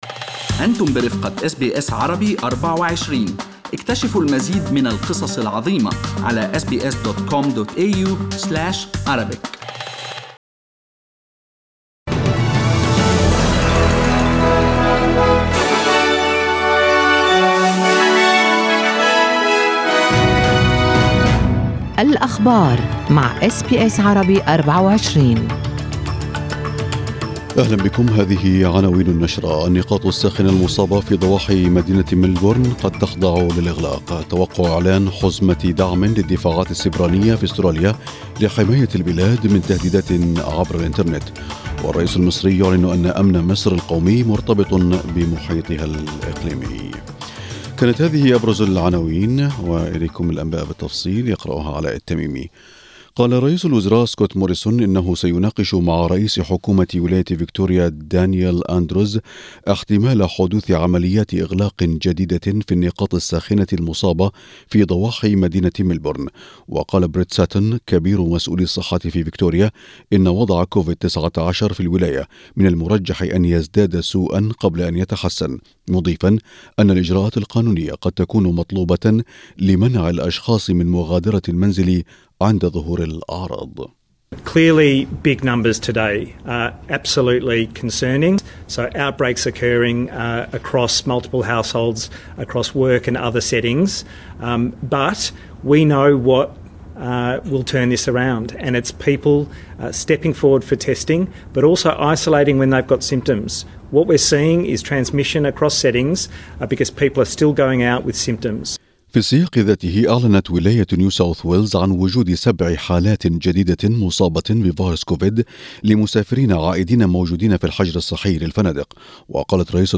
نشرة أخبار الصباح 30/6/2020